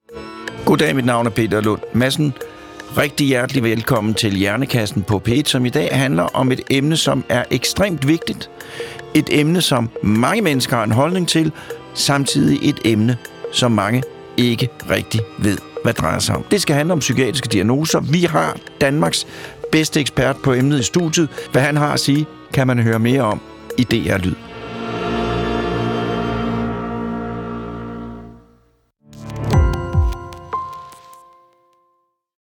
Peter Lund Madsen får hver uge besøg af eksperter i studiet. Alle emner kan blive belyst - lige fra menneskekroppens tarmflora til ekspeditioner på fremmede planeter.